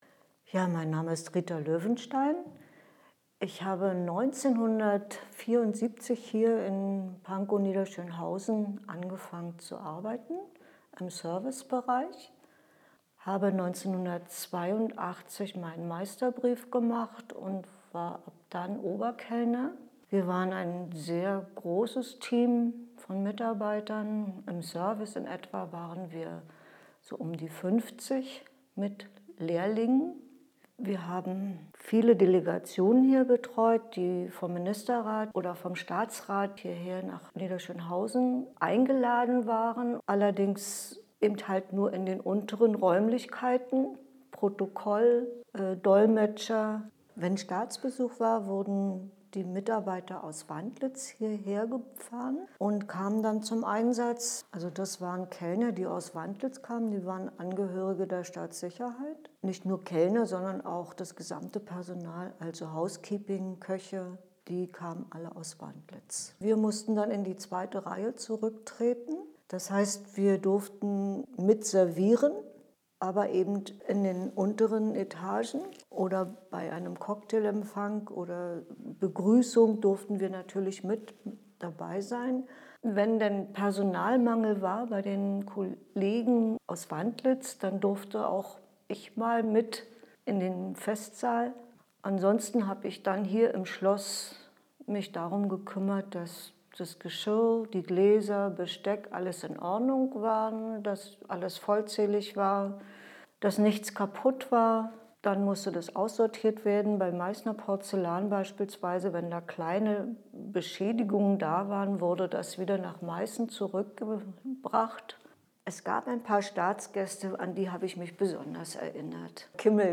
Die Oberkellnerin der Staatsgäste – eine Zeitzeugin erzählt
Die Oberkellnerin erinnert sich...